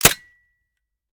weap_decho_fire_last_plr_mech_03.ogg